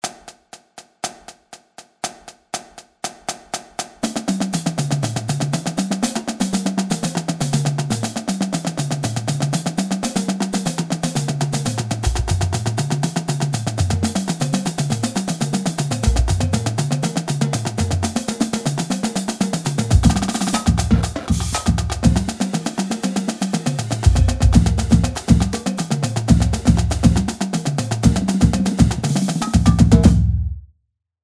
Stick Control 2 - 120.mp3